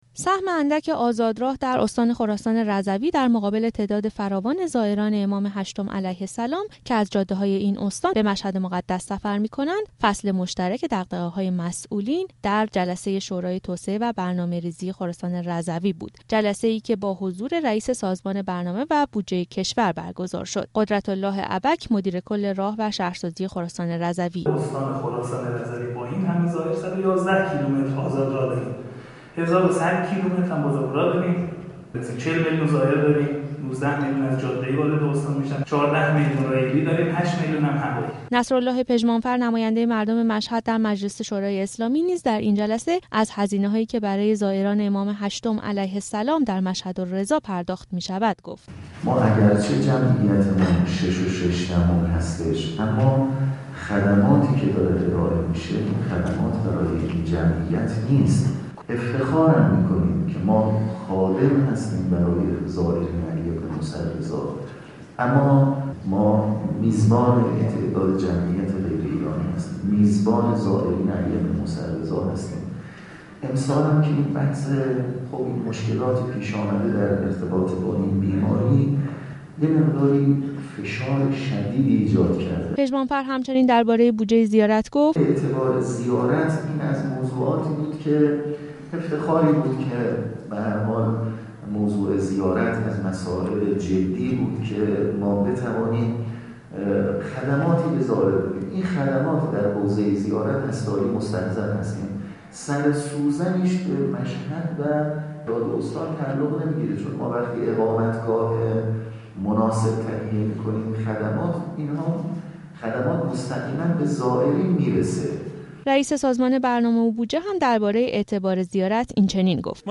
به گزارش خبر رادیو زیارت، بودجه زیارت موضوعی بود که در جلسه امروز شورای برنامه ریزی خراسان رضوی با حضورمحمد باقر نوبت رئیس سازمان برنامه و بودجه کشور مطرح شد. گزارش خبرنگار رادیو زیارت از این جلسه را بشنوید: